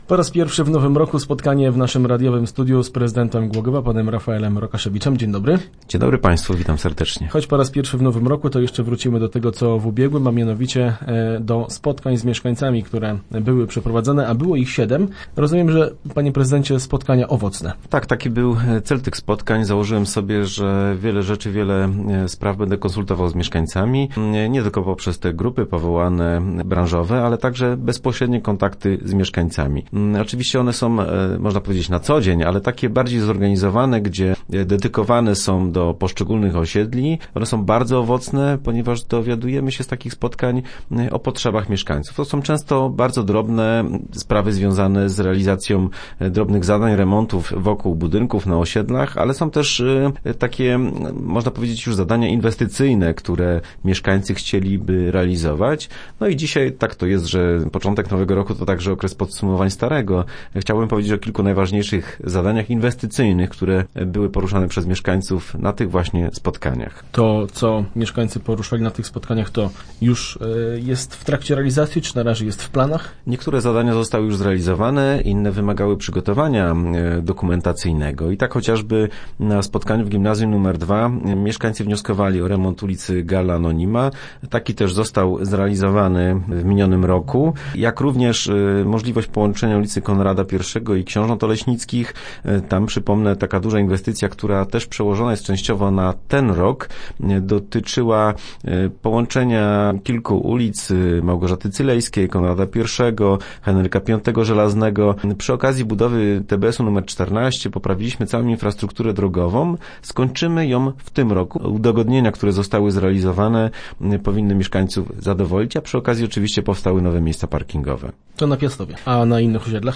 Teraz w radiowym studiu przybliżył problematykę poruszaną przez głogowian oraz inwestycje, które już są w trakcie realizacji.